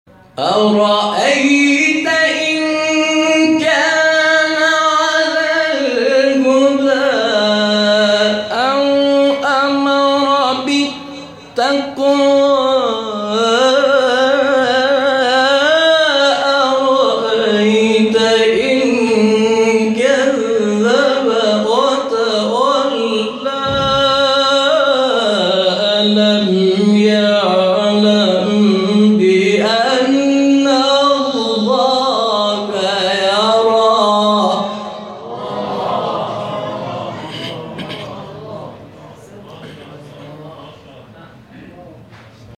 نغمات صوتی از قاریان ممتاز کشور
در مقام حجاز